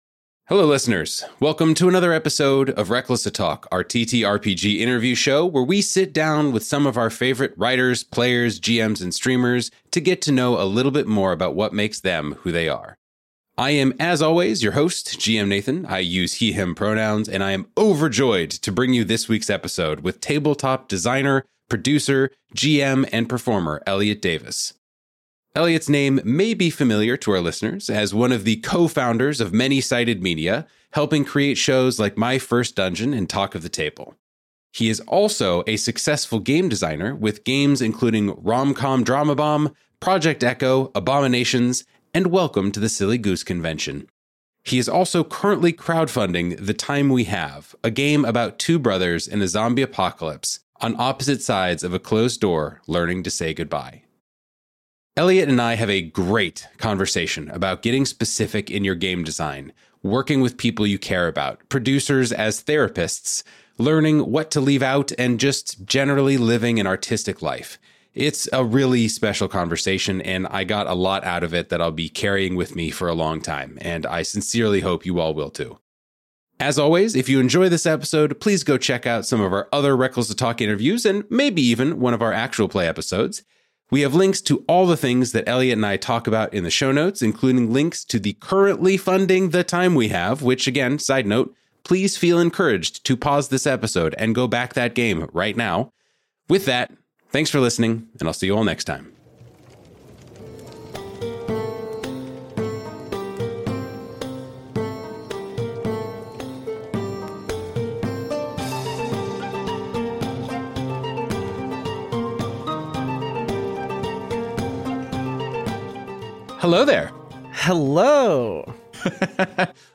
Welcome to another episode of Reckless A-Talk, our TTRPG interview show where we sit down with some of our favorite writers, players, GMs, and streamers to get to know a little bit more about what makes them who they are.